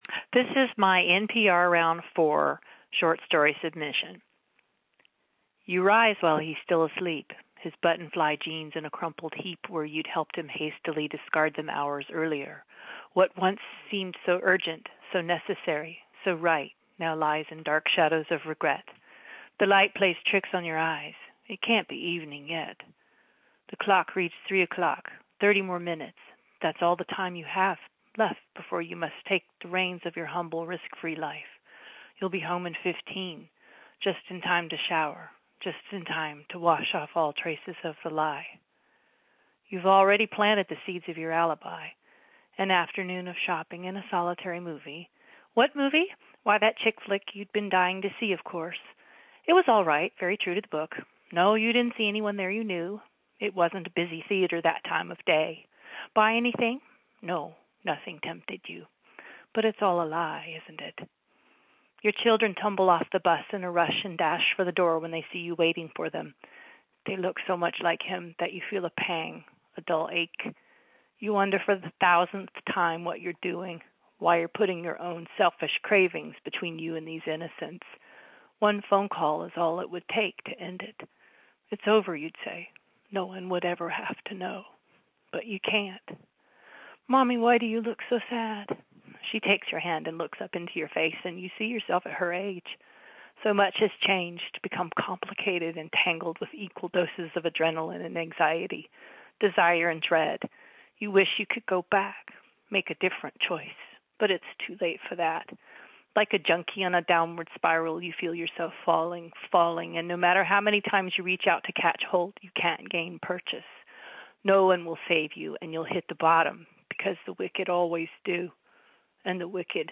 I didn’t realize how much like an old woman I sound on the phone.
I read this in about two minutes.  Should have used bigger words and/or read more slowly (sorry).